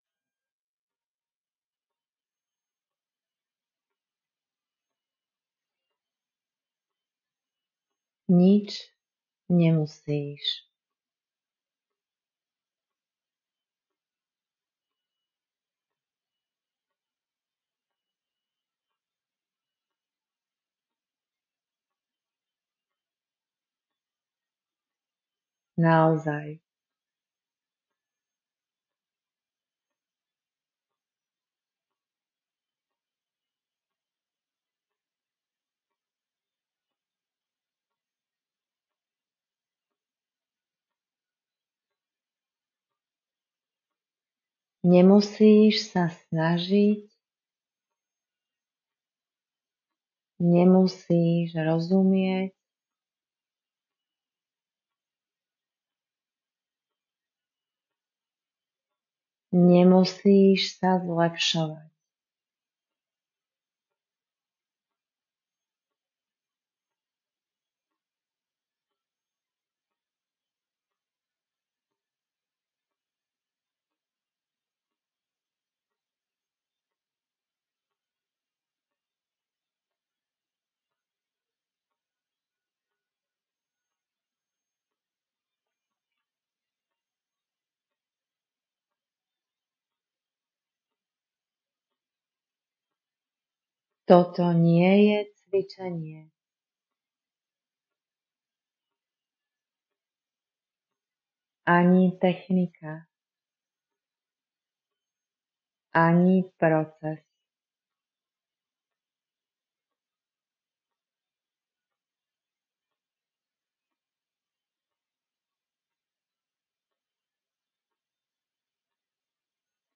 Audio cyklus „Nič nemusíš“ je séria pokojových nahrávok určených na zastavenie, uvoľnenie napätia a návrat k sebe.
Sú to stavové audiá pre ľudí, ktorí už nechcú byť tlačení k zmene, riešeniam ani výkonu.